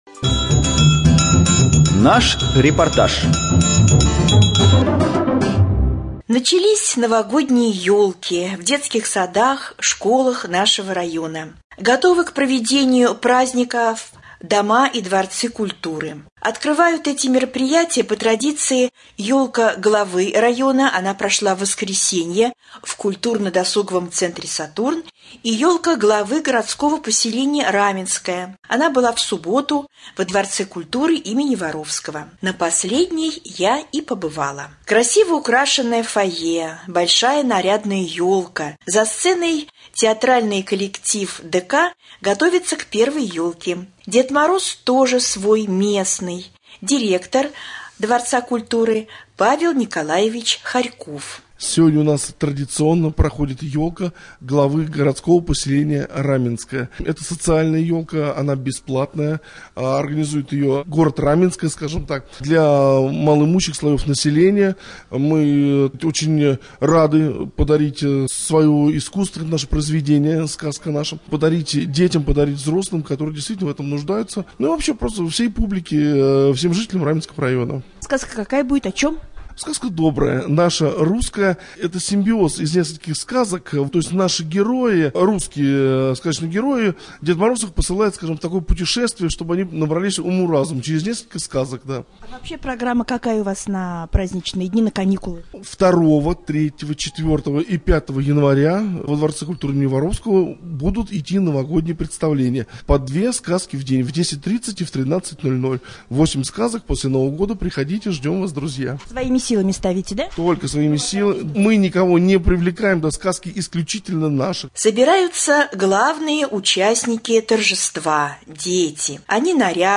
5.Рубрика «Специальный репортаж». В ДК им.Воровского состоялась елка Главы городского поселения Раменское.